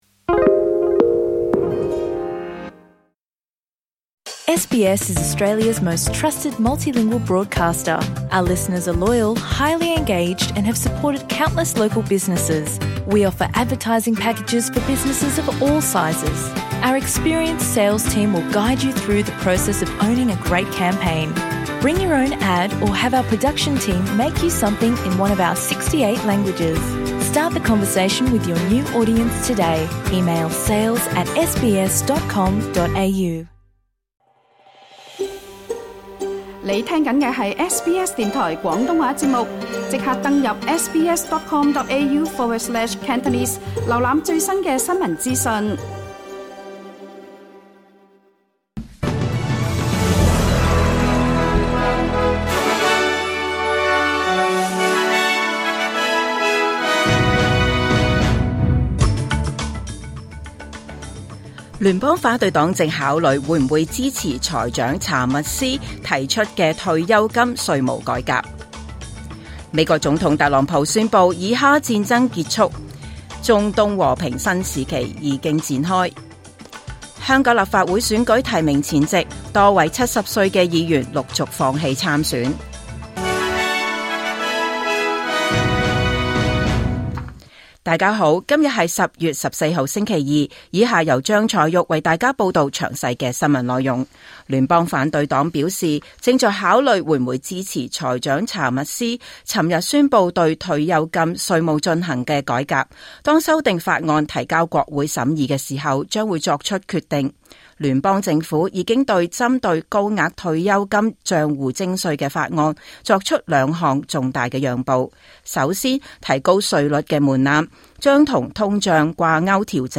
2025年10月14日SBS廣東話節目九點半新聞報道。